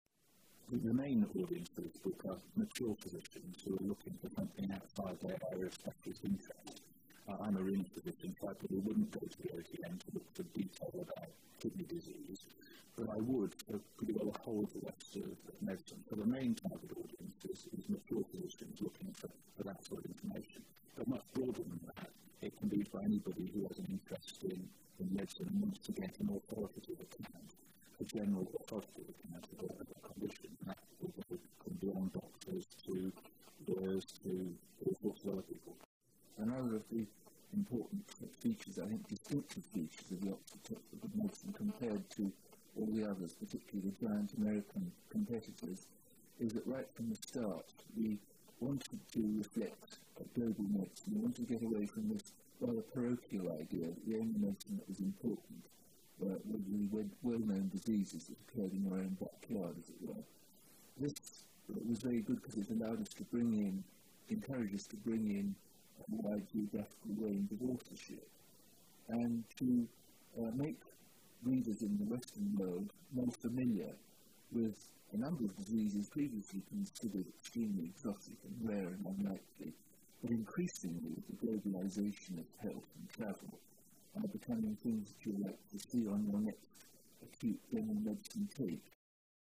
So I hope that this interview will be of interest to medics and non-medics alike.